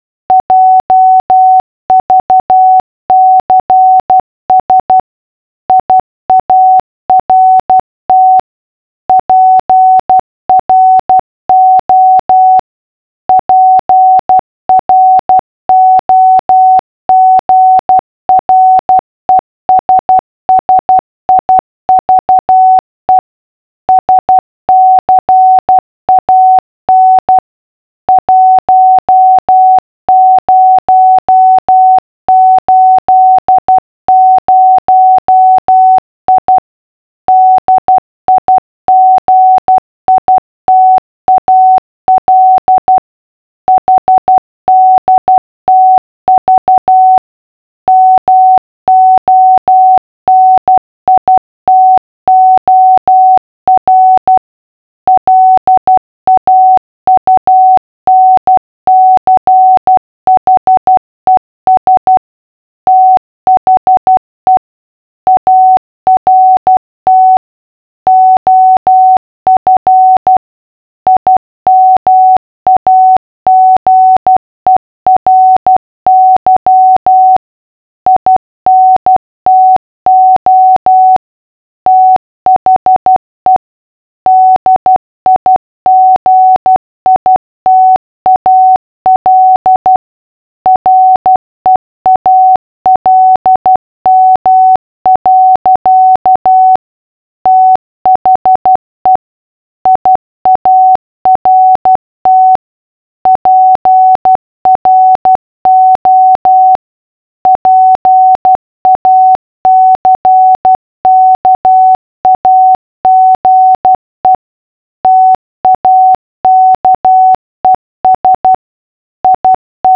下の１級国試編というテープには欧文普通文が60字/分と70字/分で入っている。
画像をクリックするとモールスが聞けます。60字/分ってこんな感じです。けっこう遅いんですが書き取ろうとすると難しい・・・